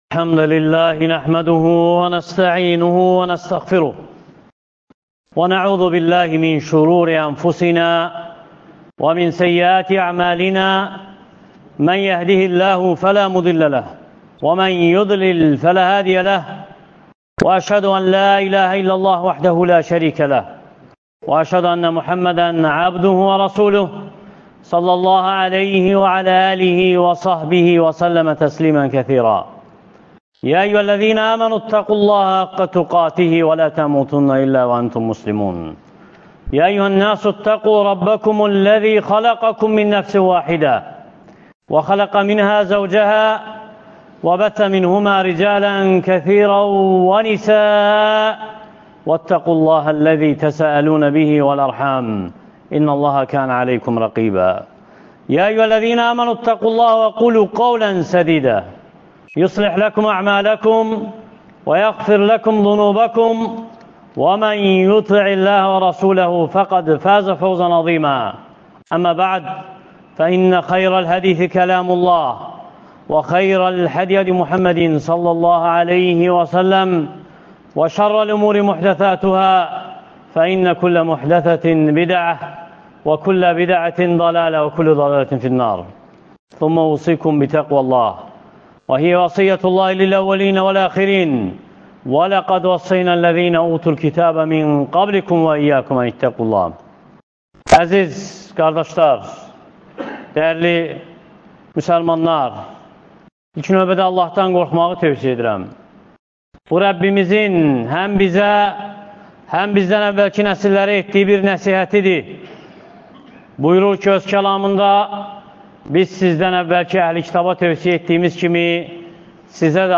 Aşura gününün fəziləti (Cümə xütbəsi — 12.07.2024) | Əbu Bəkr məscidi